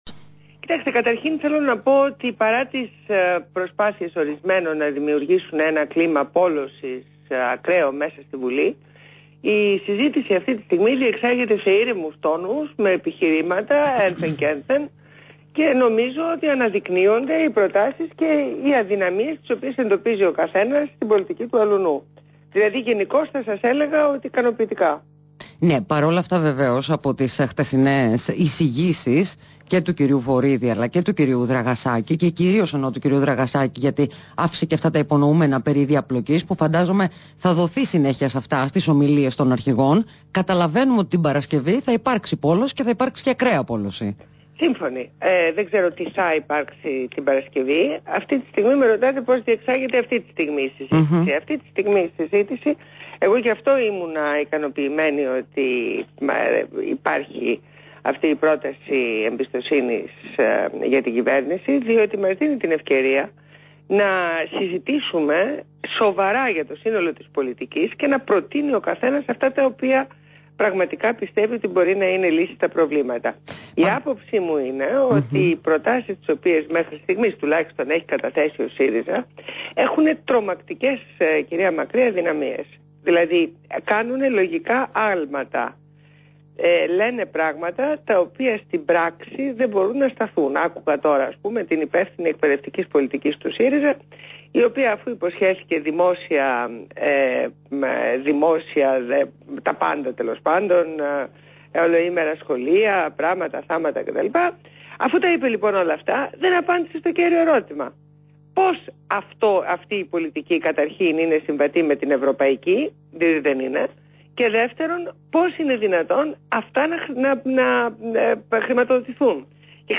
Στο ραδιόφωνο REALfm